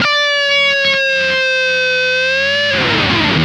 Index of /90_sSampleCDs/Zero G - Funk Guitar/Partition I/VOLUME 001